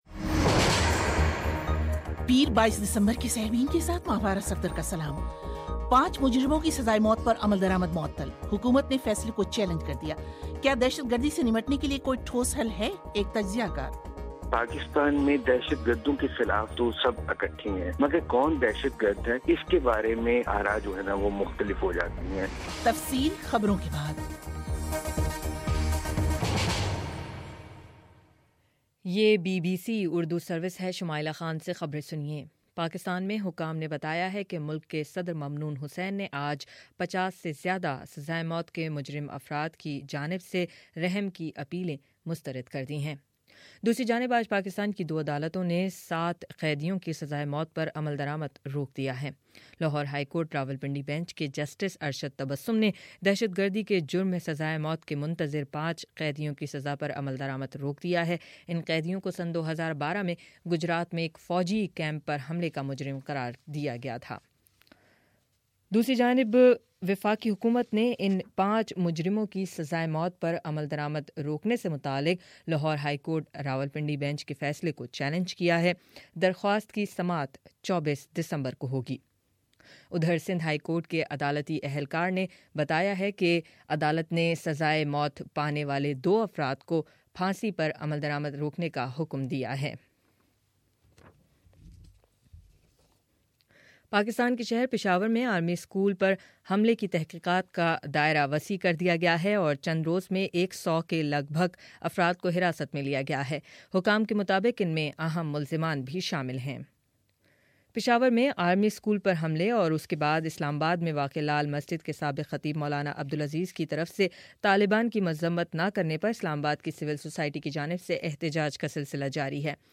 پیر:22 دسمبرکا سیربین ریڈیو پروگرام